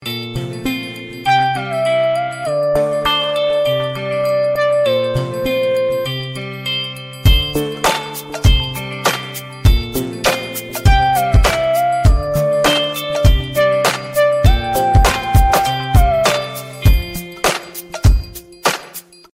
TONO DE TELEFONO es un Tono para tu CELULAR